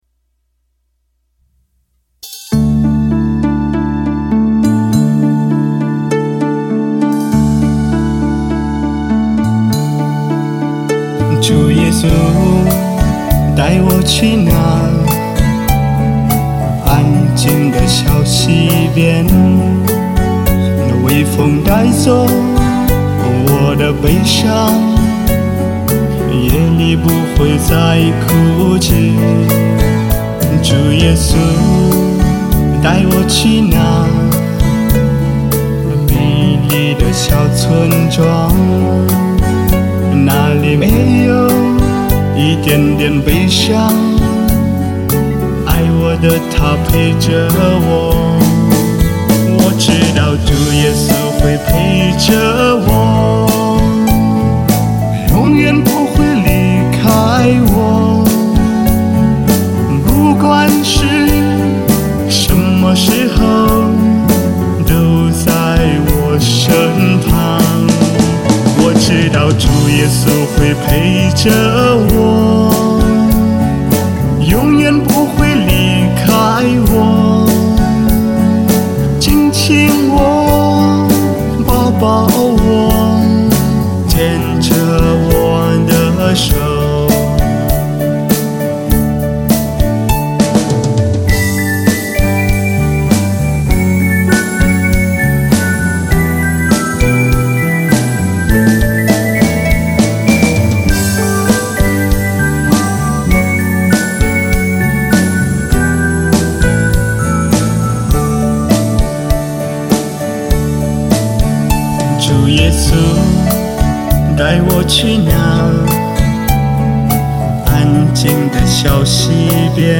【原创圣歌】